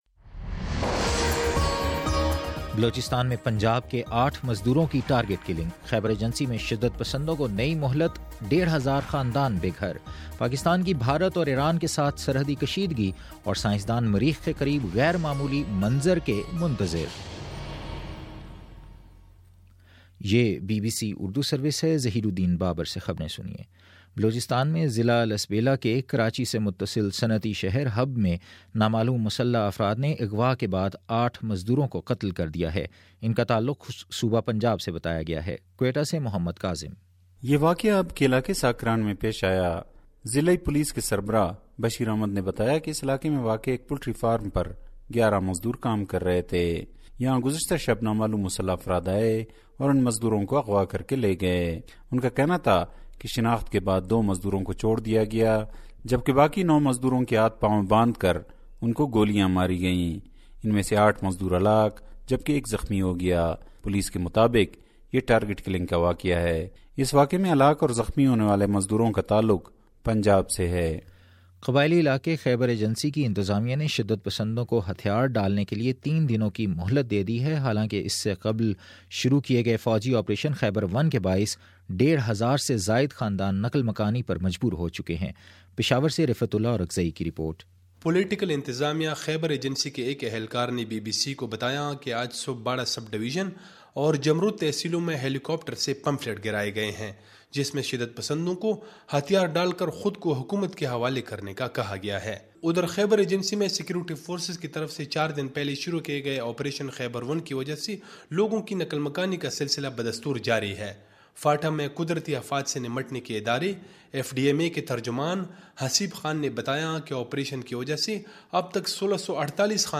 اکتوبر19: شام چھ بجے کا نیوز بُلیٹن
دس منٹ کا نیوز بُلیٹن روزانہ پاکستانی وقت کے مطابق صبح 9 بجے، شام 6 بجے اور پھر 7 بجے۔